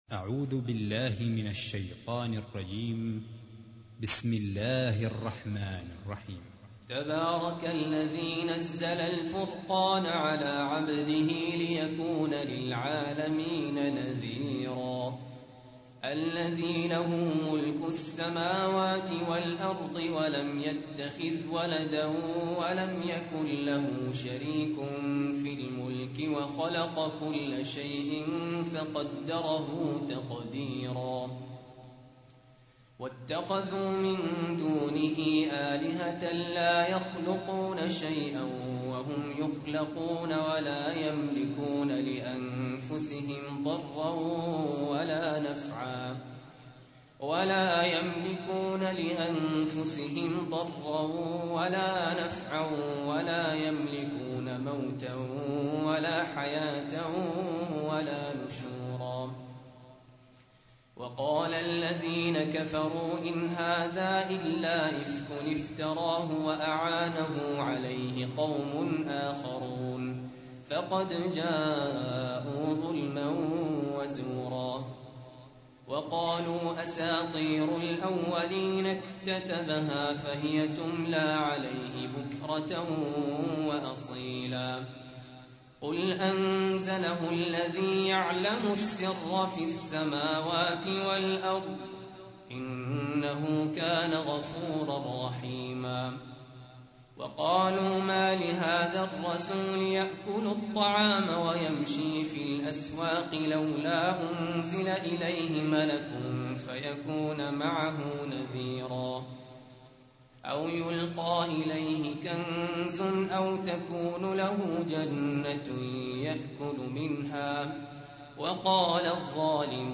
تحميل : 25. سورة الفرقان / القارئ سهل ياسين / القرآن الكريم / موقع يا حسين